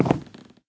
wood5.ogg